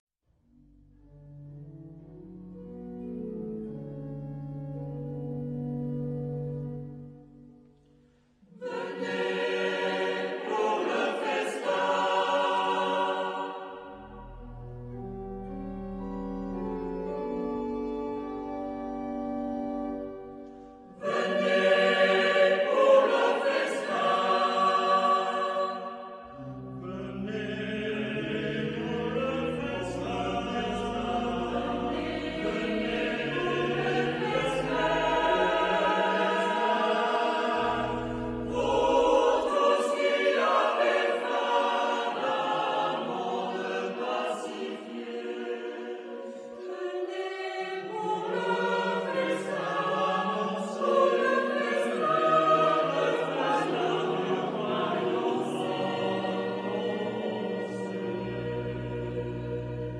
Genre-Style-Forme : Sacré ; Cantique
Type de choeur : SATB  (4 voix mixtes )
Instrumentation : Orgue  (1 partie(s) instrumentale(s))
Tonalité : sol majeur